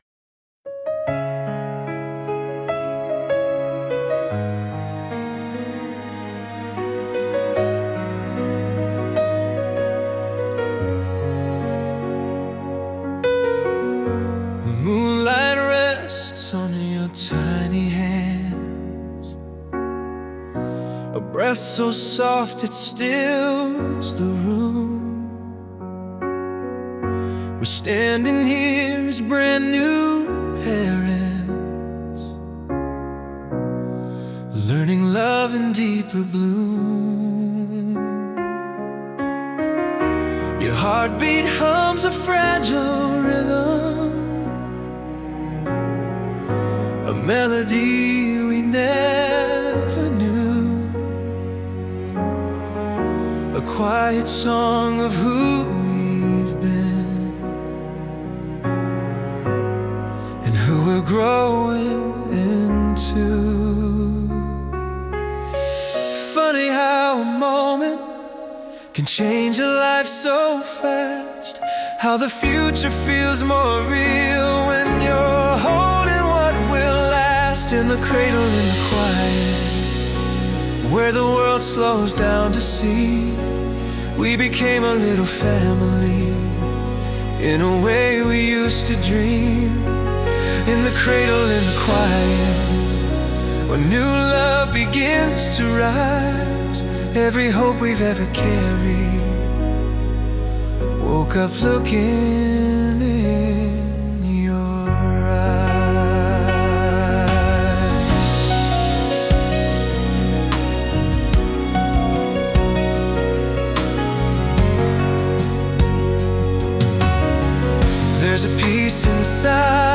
Explore Our Acoustic Tracks